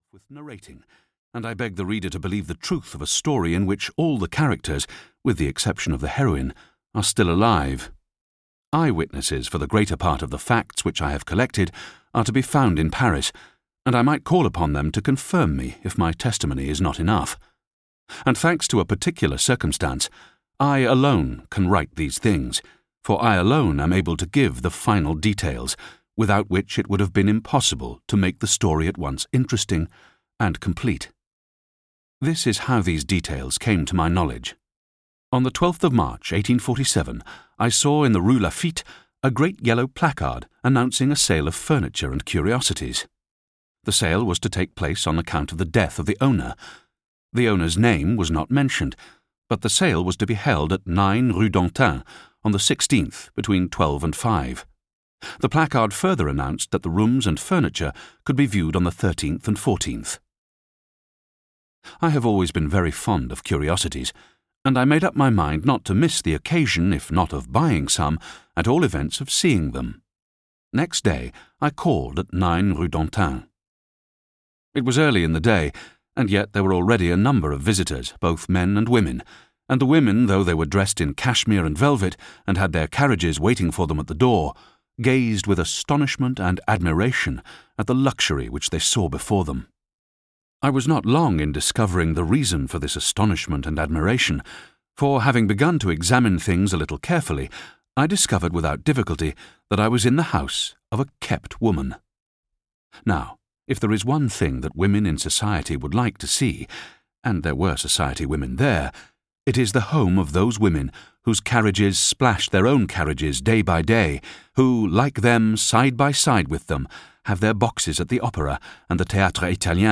Audio knihaLa Dame aux Camélias (EN)
Ukázka z knihy